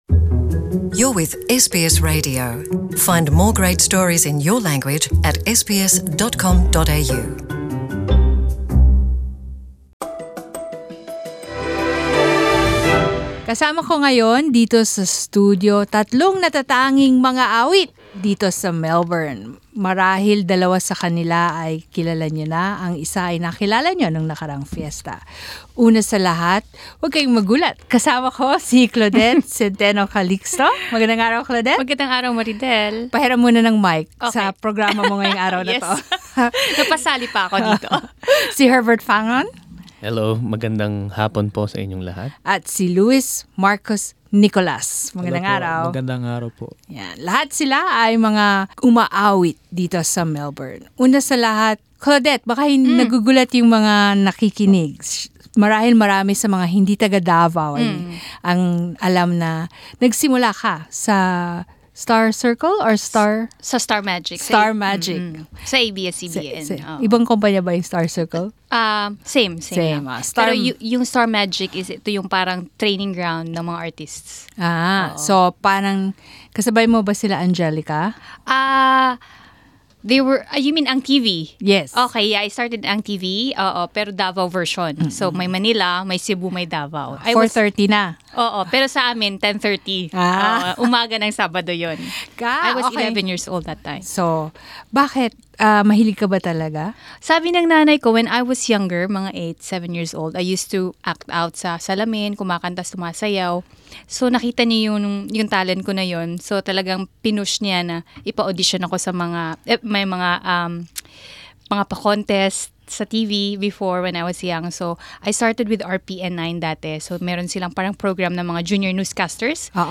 Come and sing along….